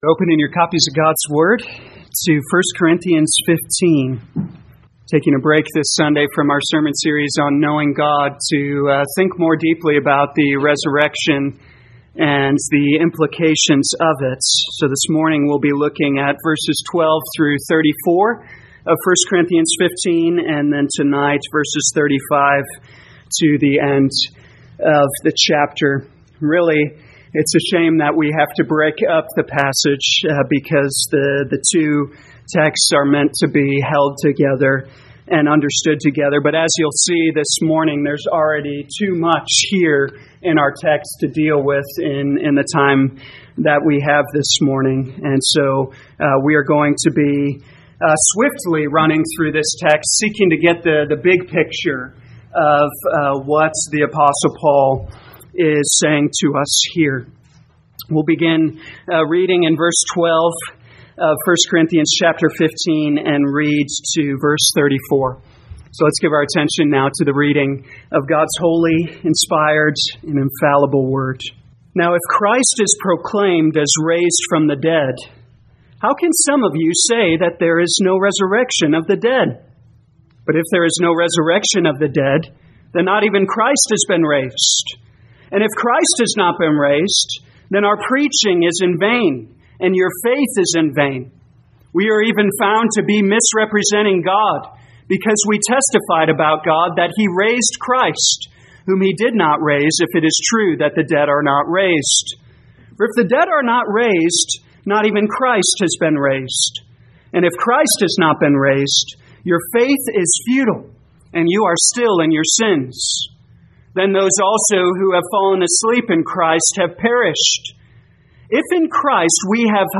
2023 1 Corinthians Morning Service Download